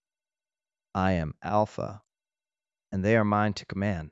novafarma/assets/audio 🔴/voiceover/prologue/prologue_17.wav